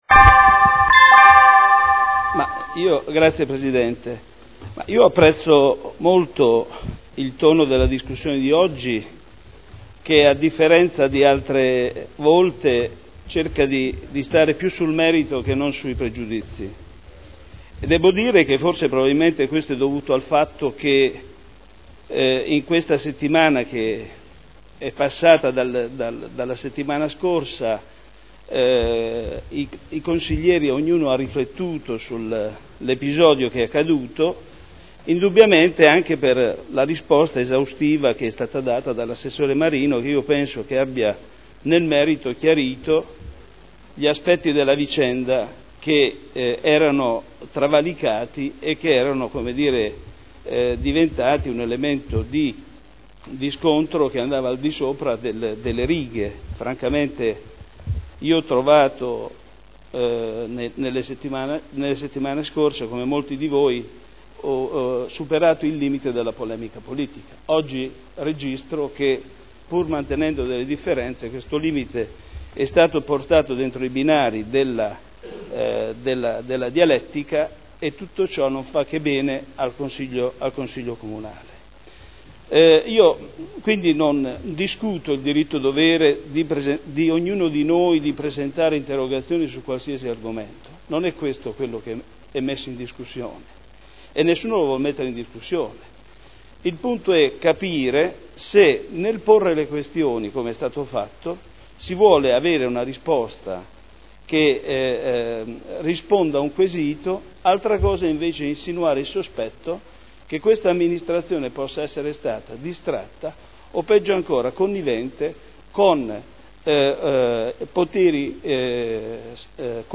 Michele Andreana — Sito Audio Consiglio Comunale